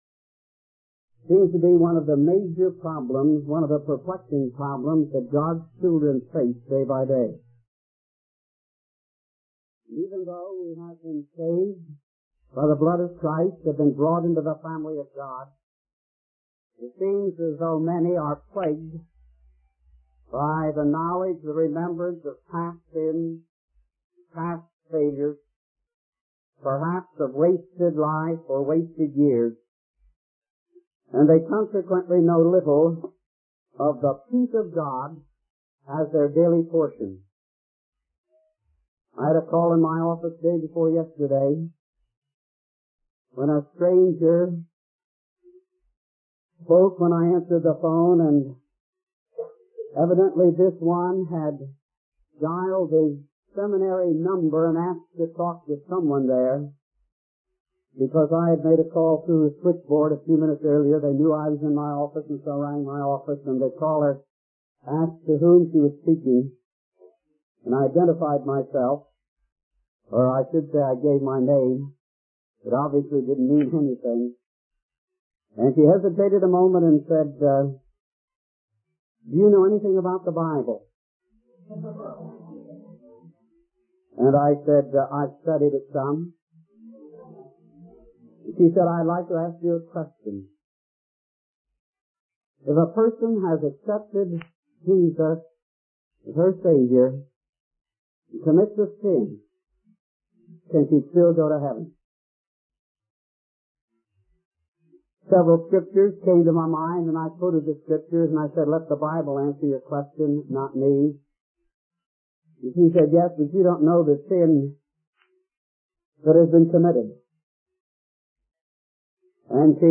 In this sermon, the preacher emphasizes the doctrine of the blood of Christ and its power to dismiss all sins from God's memory. He explains that while humans cannot dismiss guilt from their minds, God can and does dismiss the memory of sins covered by the blood of Christ.